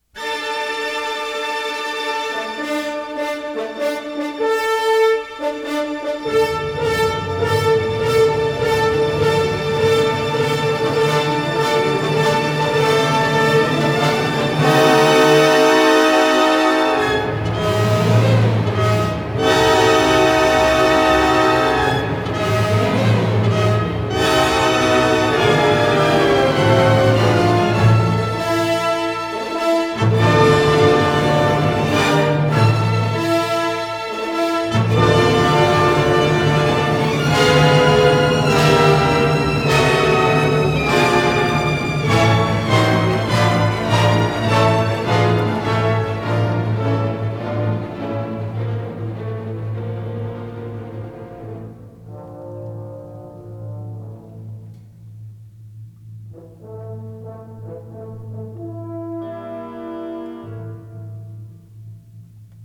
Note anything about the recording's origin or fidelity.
Stereo recording made in February 1960